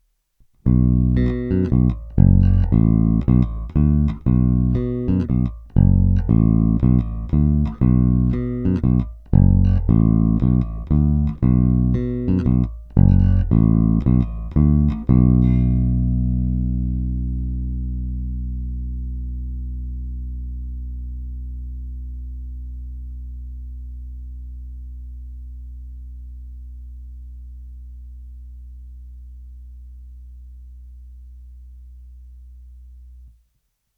Opět nejdříve jen rovnou do zvukovky a ponecháno bez úprav, jen normalizováno.
Oba snímače